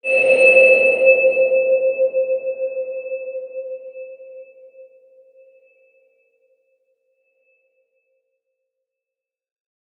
X_BasicBells-C3-mf.wav